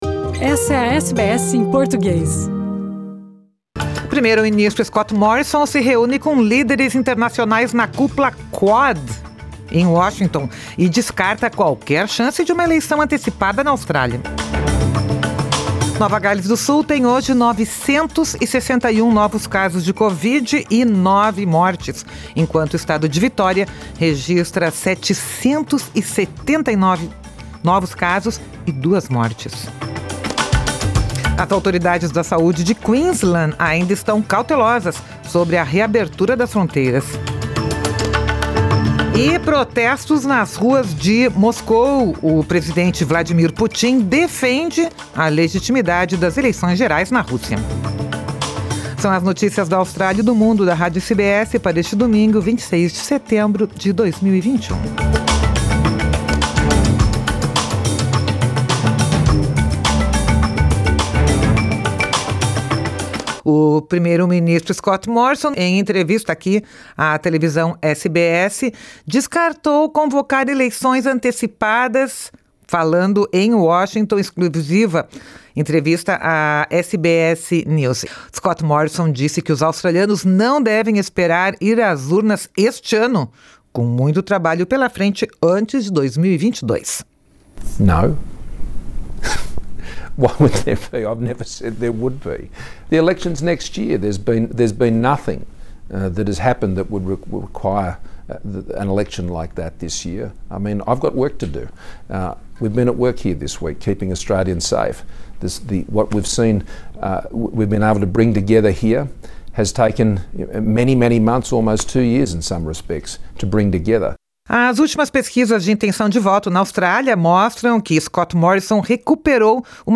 Vladimir Putin defende a legitimidade das eleições gerais na Rússia. São as notícias da Austrália e do Mundo da Rádio SBS para este domingo, 26 de setembro de 2021.